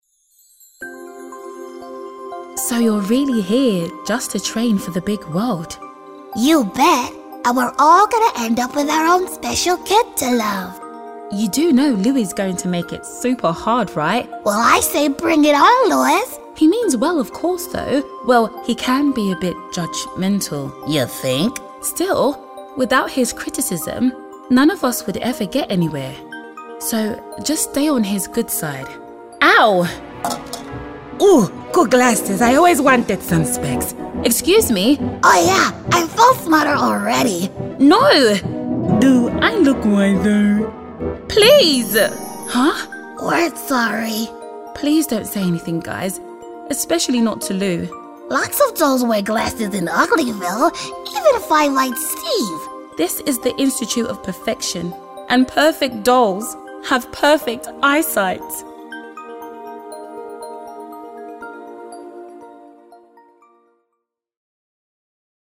Showreel
Female / 20s, 30s / African, English, Nigerian / London, Southern Showreel https